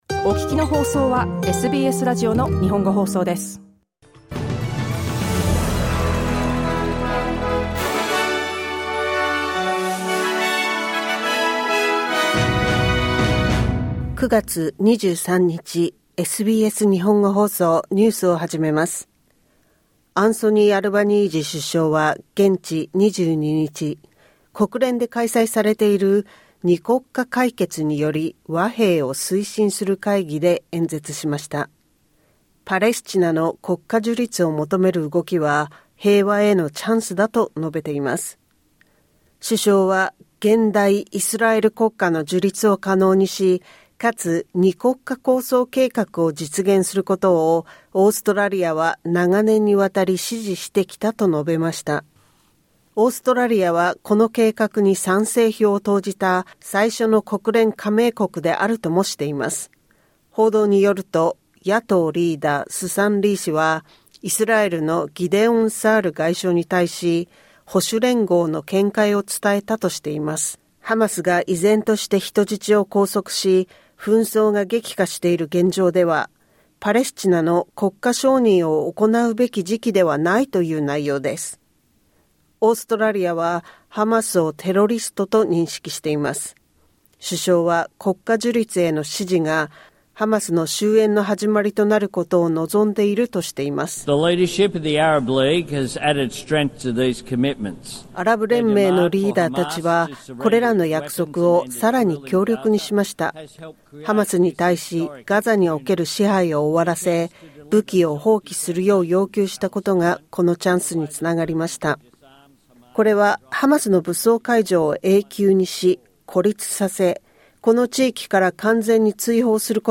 SBS日本語放送ニュース9月23日火曜日